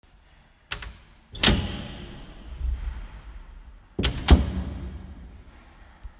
Przyporządkujcie numerki do właściwych dźwięków: łamanie hostii, przewracanie stron w Mszale, zamykanie drzwi w kościele, dzwonki, wlewanie wody do kielicha, otwieranie drzwi do konfesjonału, gong, machanie kadzidłem.